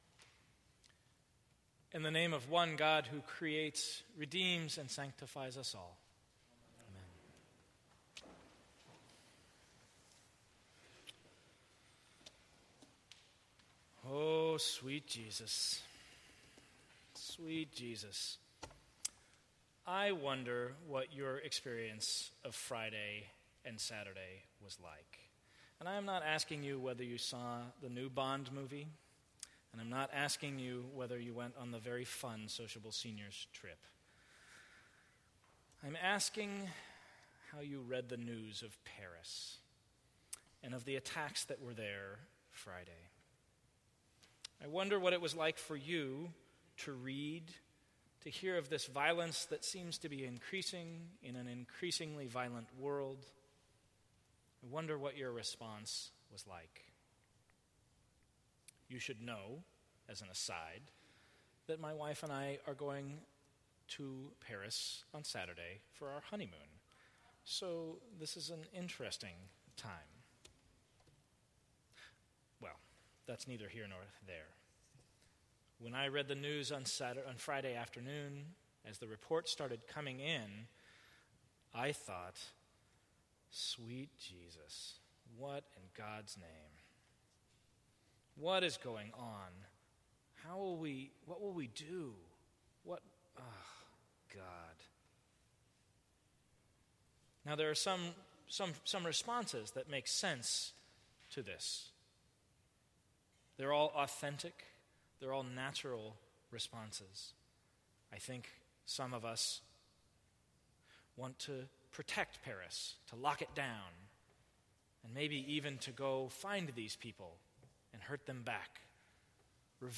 Sermons from St. Cross Episcopal Church Is this the end of the world?